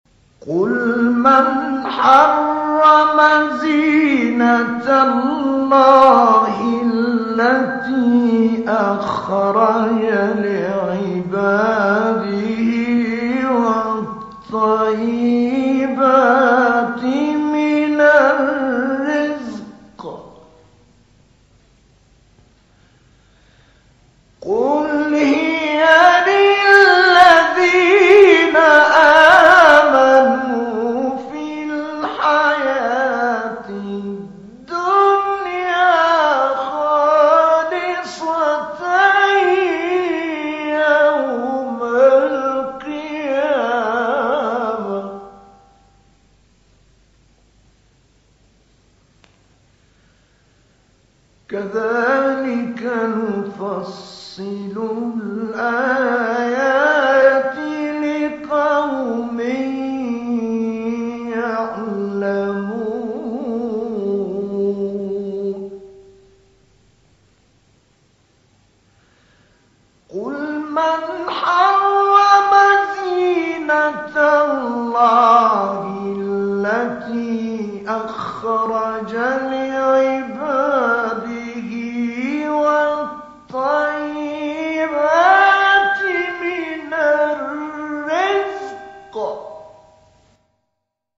مقام نهاوند استاد طه الفشنی | نغمات قرآن | دانلود تلاوت قرآن